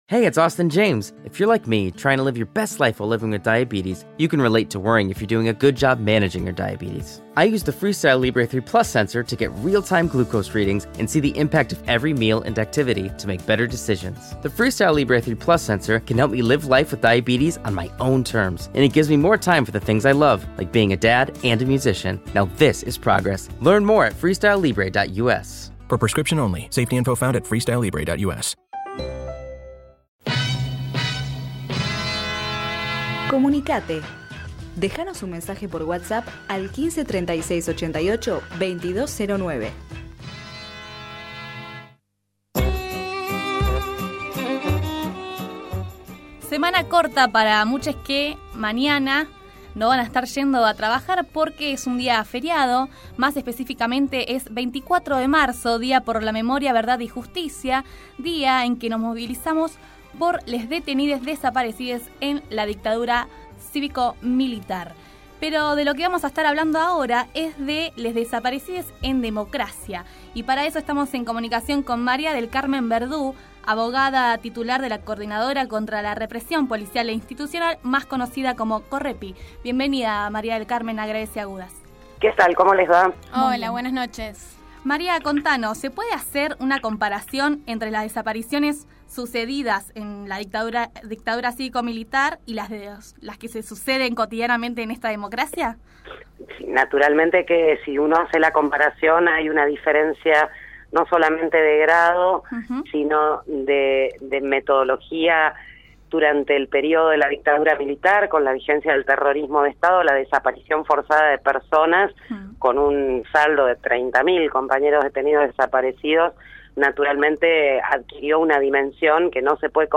Graves y agudas / Entrevista